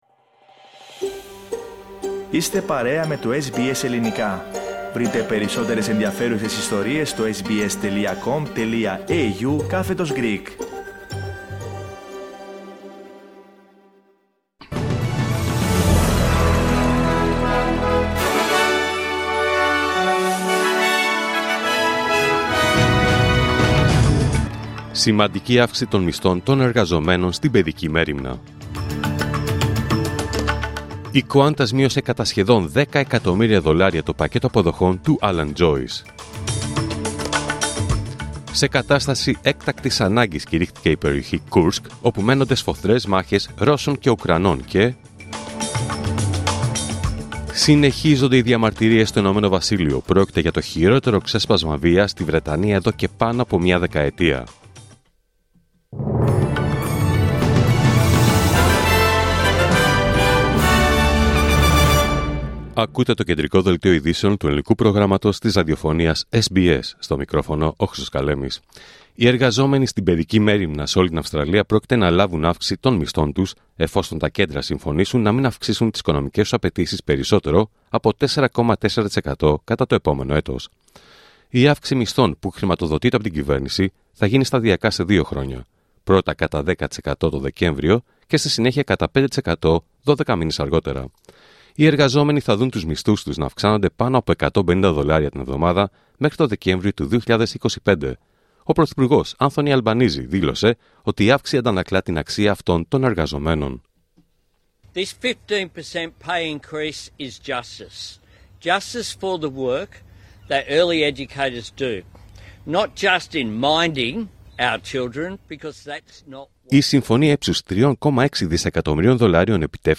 Δελτίο Ειδήσεων Πέμπτη 8 Αυγούστου 2024